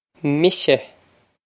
Mise misha
This is an approximate phonetic pronunciation of the phrase.